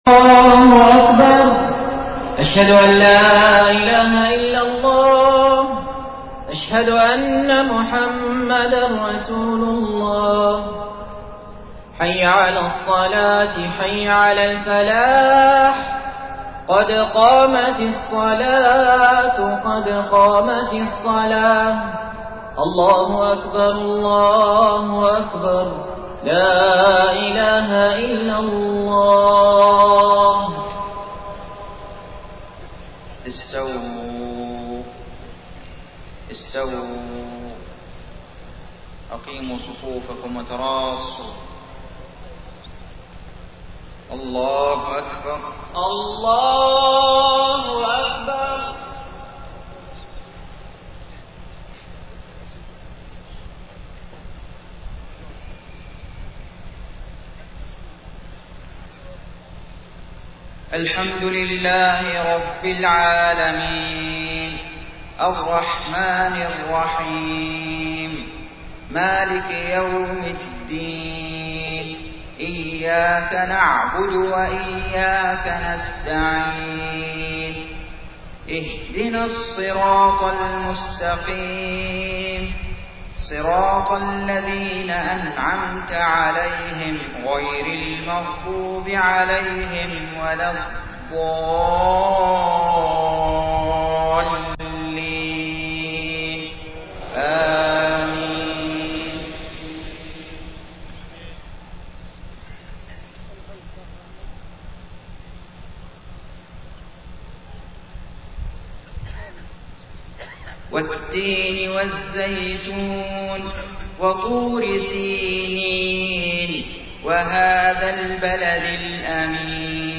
صلاة المغرب 13 ربيع الأول 1431هـ سورتي التين و الماعون > 1431 🕋 > الفروض - تلاوات الحرمين